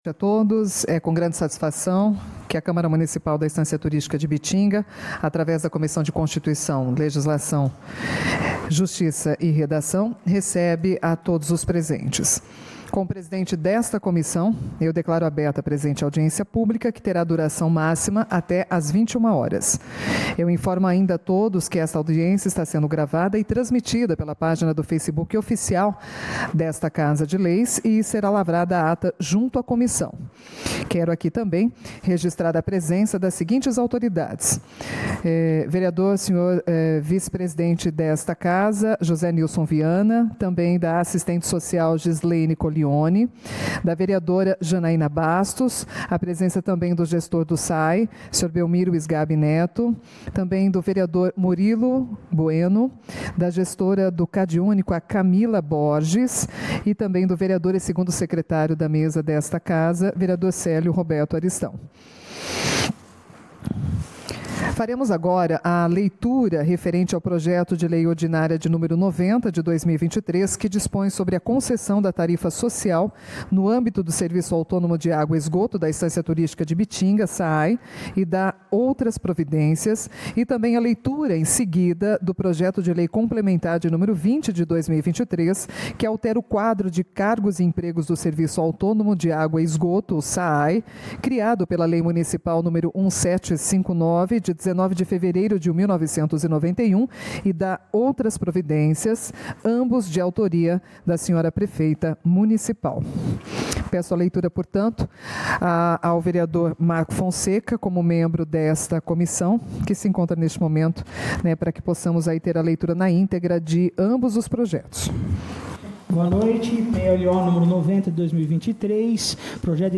Audiencia Pública da Comissão de Constituição, Legislação Justiça e Redação